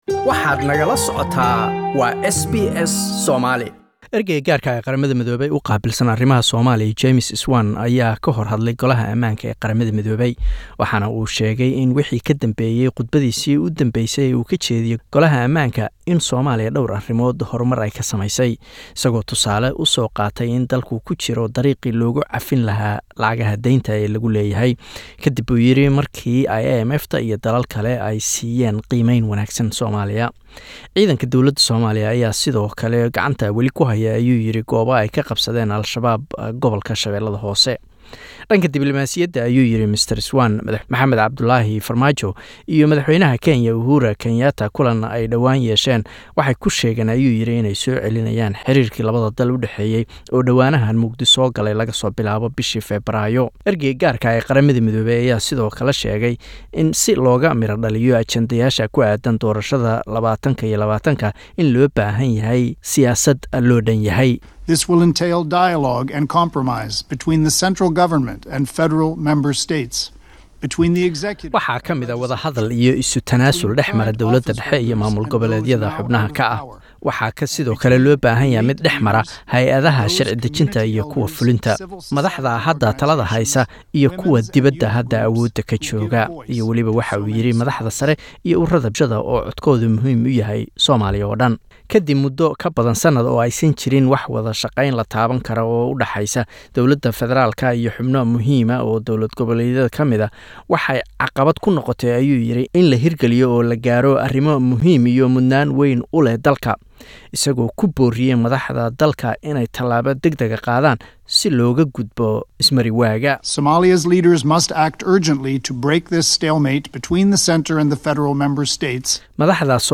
UN envoy to Somalia, James Swan speech at UN security council